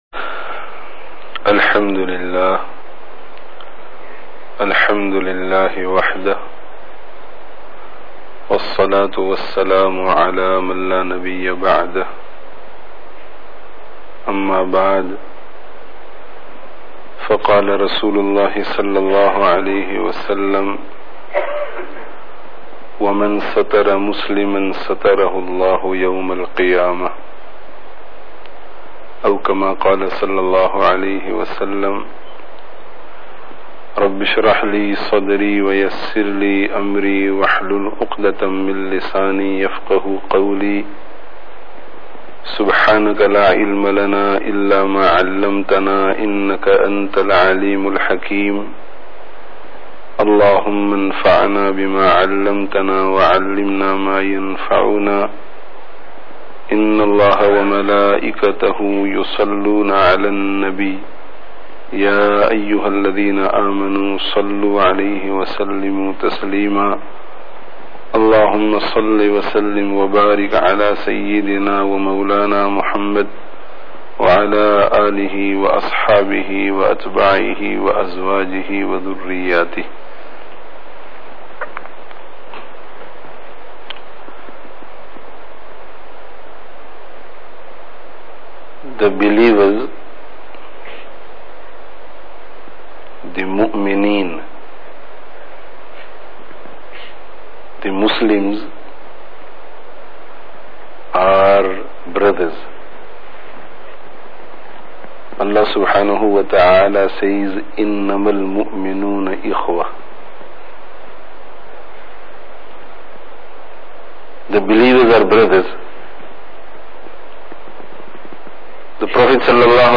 Dars of Hadith